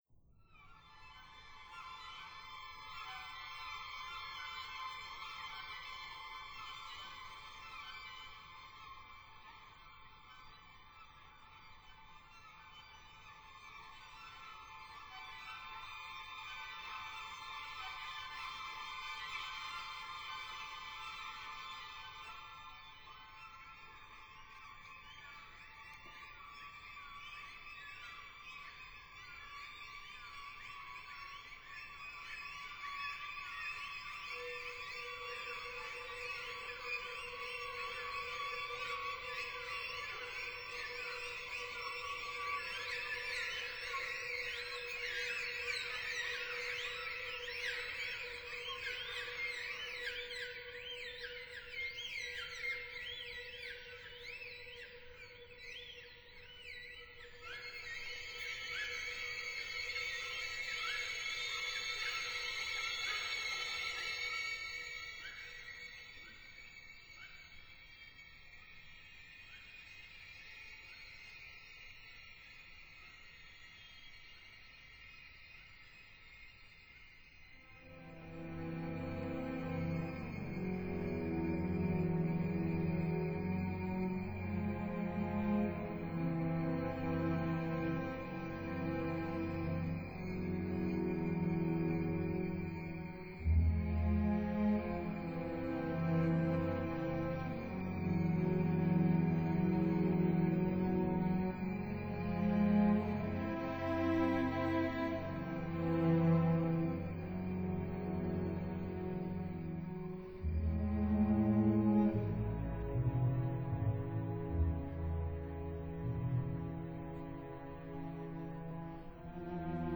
didjeridu